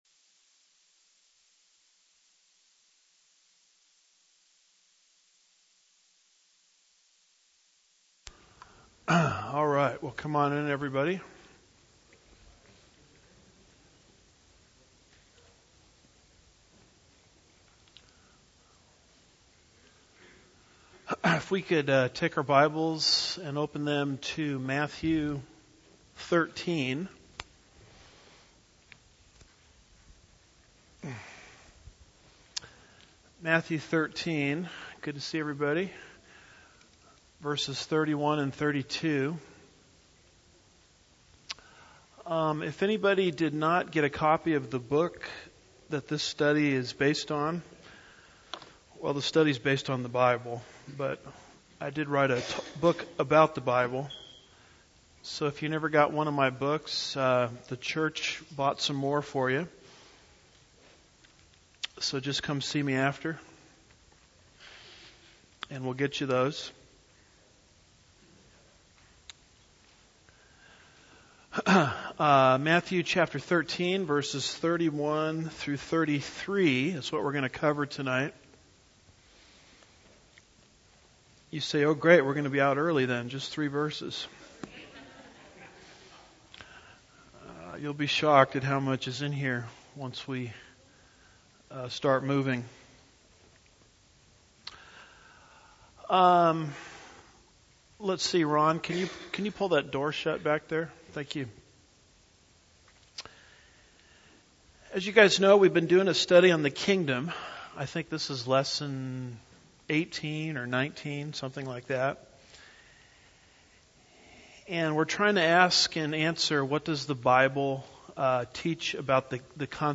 9-13-17 Matthew 13:31-33 Lesson 19